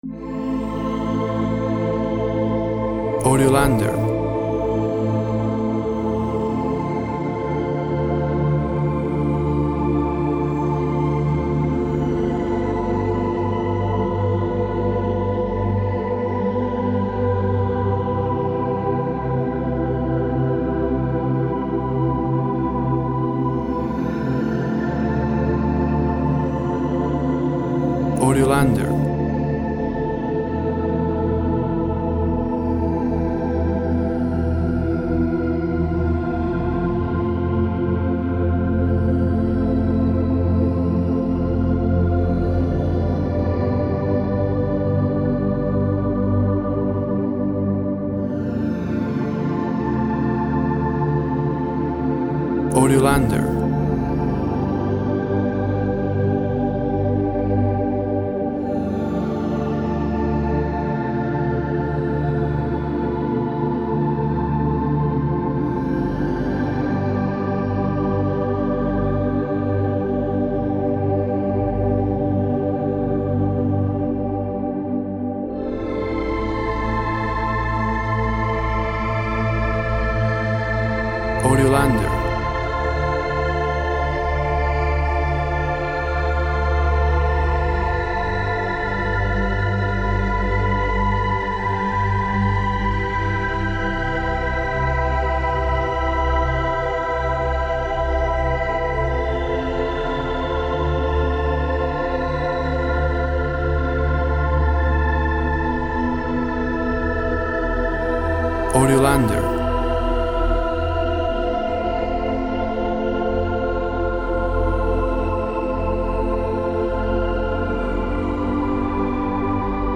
Synth strings create a quiet and contemplative mood.
Tempo (BPM) 40